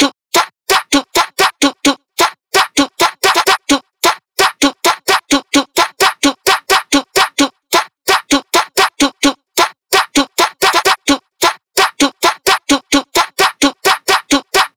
TROPKILLAZ_130_beatbox_loop_baile_06_Cmin.wav